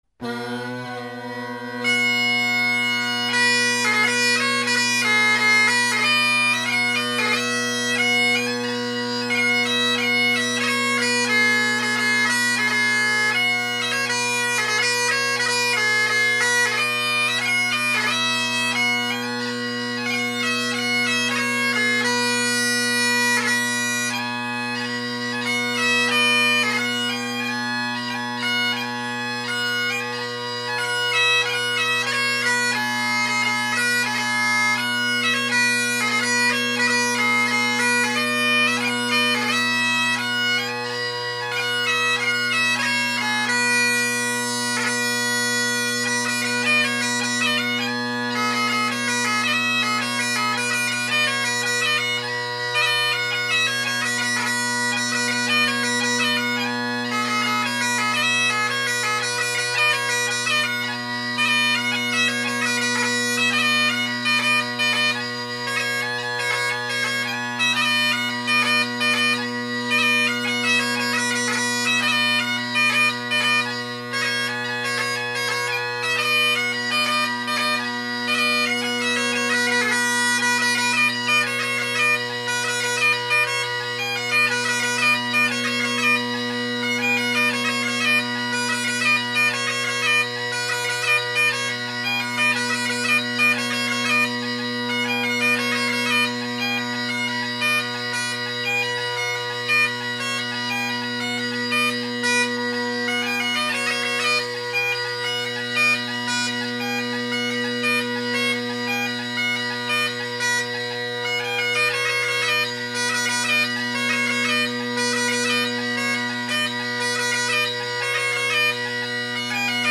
Great Highland Bagpipe Solo
A bit down on practice these days and my right hand is not doing a very good job of getting back down to the chanter so there are some false Es as my right hand takes its time.